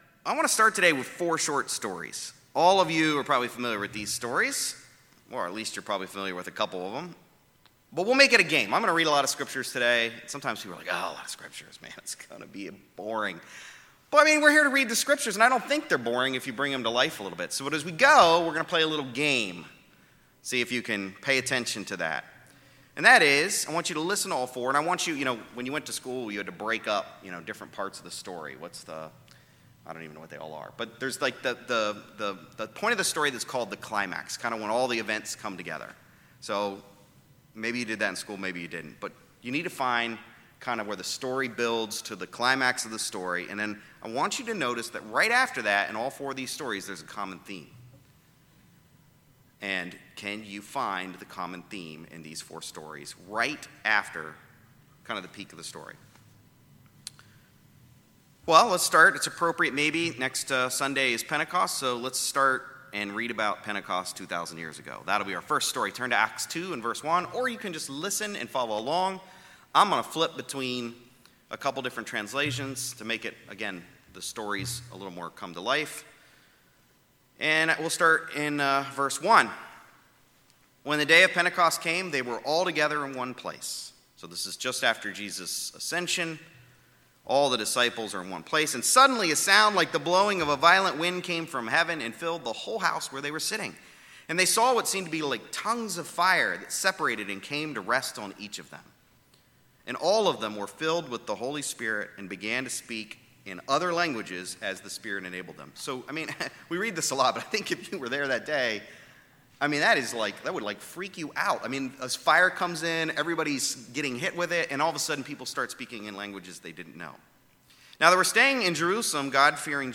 A message looking into why baptism is not optional for believers.